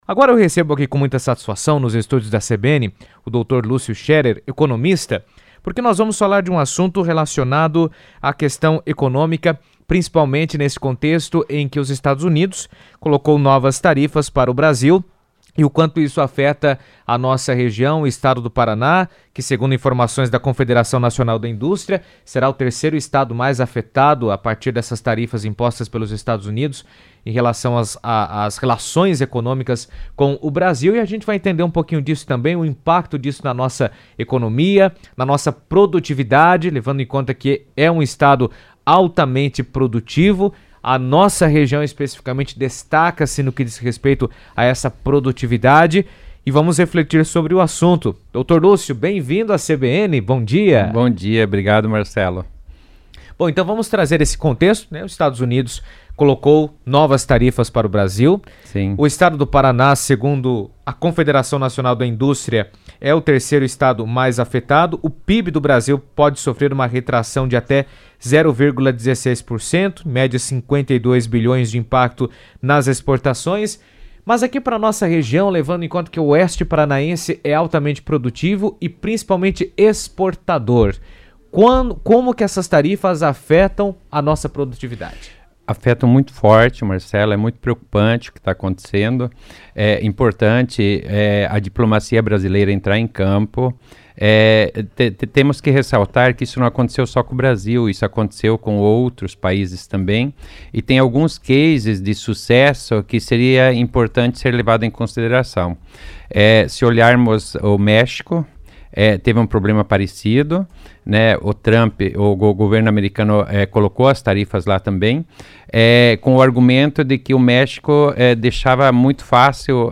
Em entrevista na CBN, ele explicou como essas medidas podem afetar as exportações e o comércio regional, ressaltando a importância de estratégias para minimizar os efeitos negativos e aproveitar oportunidades diante do cenário internacional.